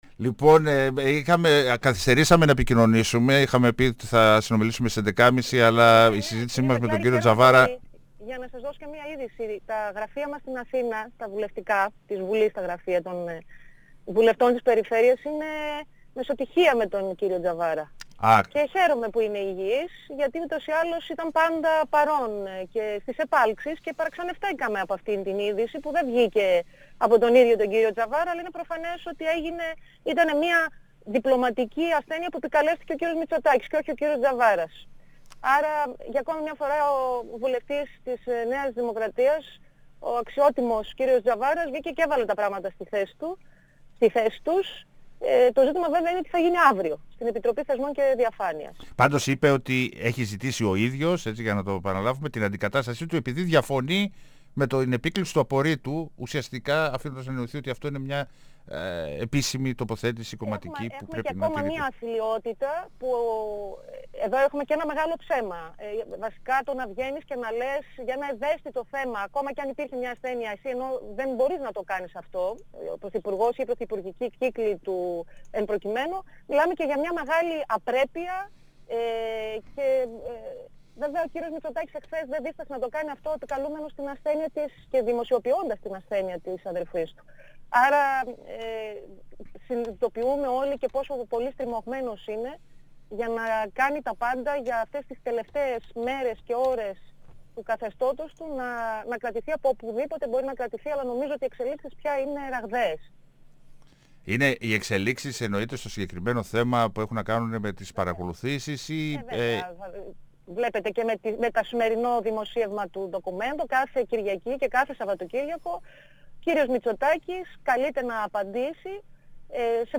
Επίθεση στον πρωθυπουργό Κ. Μητσοτάκη και την υφυπουργό Πρόνοιας Δ. Μιχαηλίδου με αφορμή τις εξελίξεις στη Δομή Αμεα Κ. Μακεδονίας «Άγιος Δημήτριος», εξαπέλυσε μιλώντας στον 102 FM της ΕΡΤ3 η βουλευτής Β ΄Θεσσαλονίκης του ΣΥΡΙΖΑ-Προοδευτική Συμμαχία Δώρα Αυγέρη. 102FM Συνεντεύξεις ΕΡΤ3